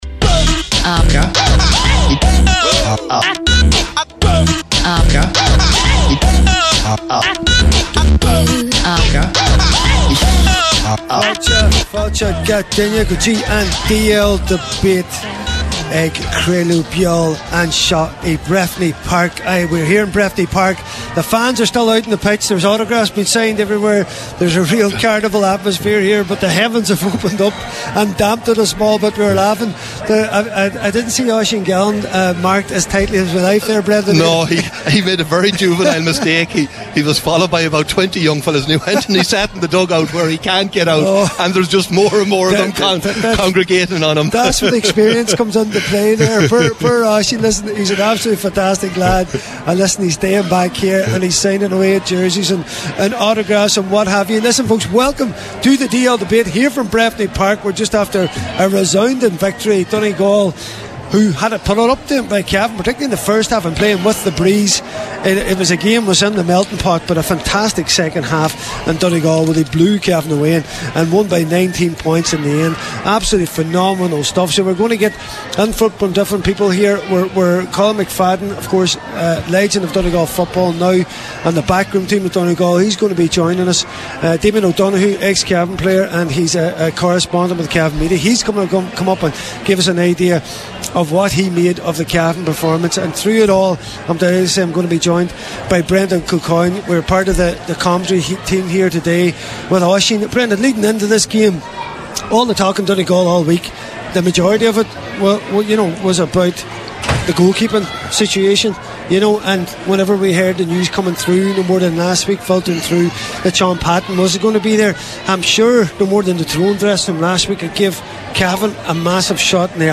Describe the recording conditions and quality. DL Debate LIVE from Kingspan Breffni Park – 01/06/25 This week’s DL Debate comes LIVE from Kingspan Breffni Park after Donegal’s 3-26 to 1-13 victory over Cavan in the All-Ireland Senior Football Championship this afternoon.